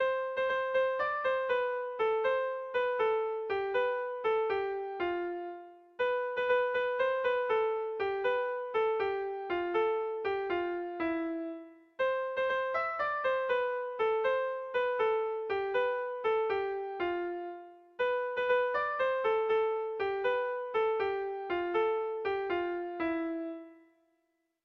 Dantzakoa